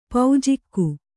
♪ paujikku